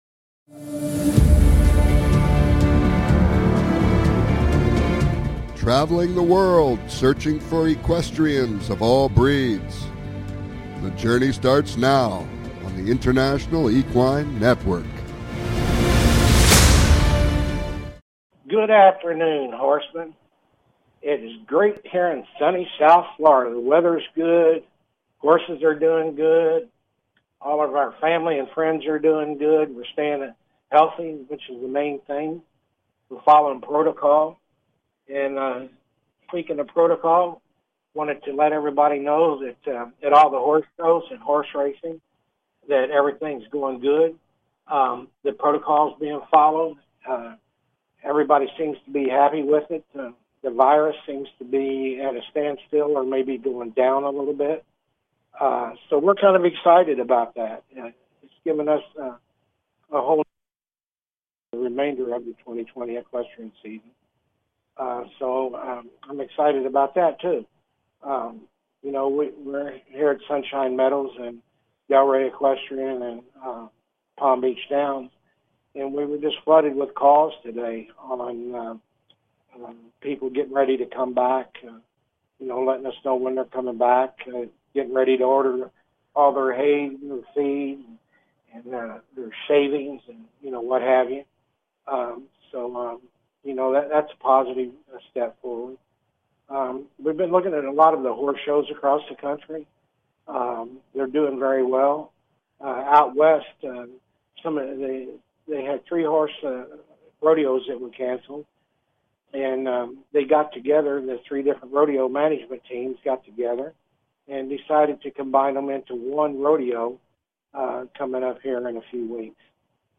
Talk Show
Calls-ins are encouraged!